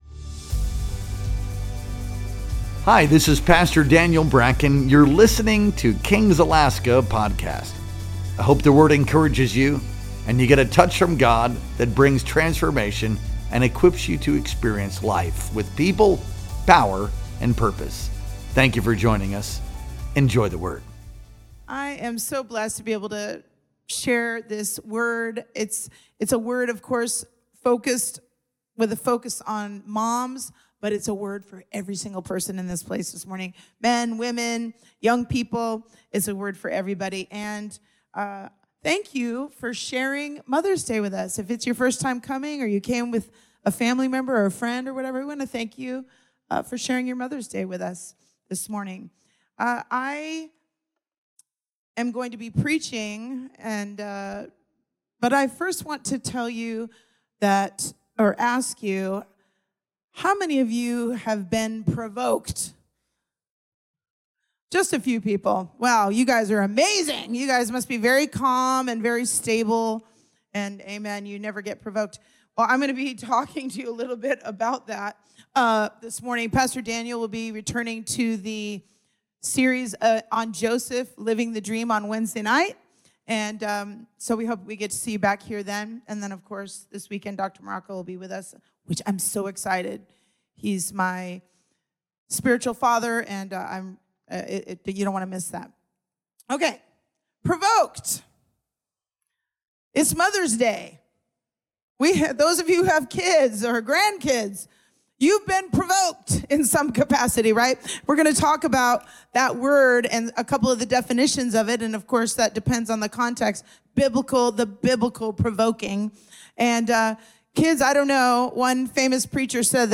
Our Sunday Worship Experience streamed live on May 11th, 2025.